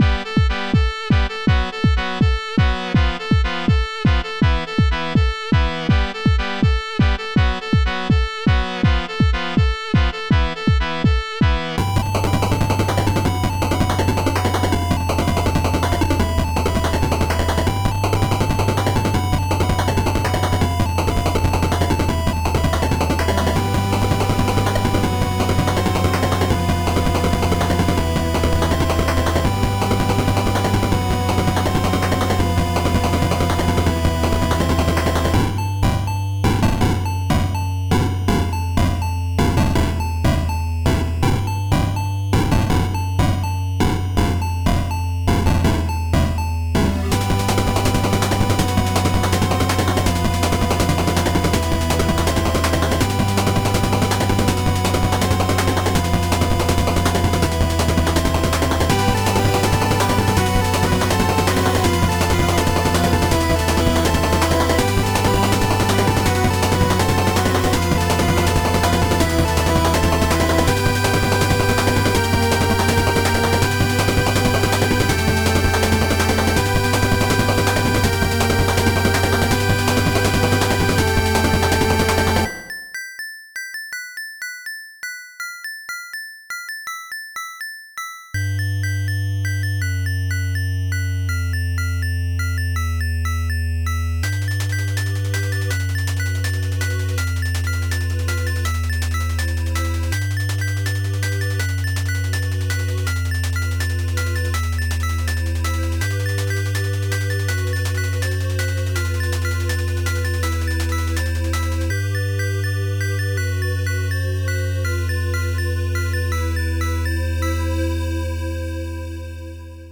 a wordless cry for help